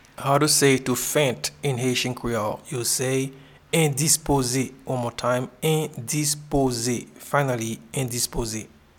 Pronunciation and Transcript:
to-Faint-in-Haitian-Creole-Endispoze.mp3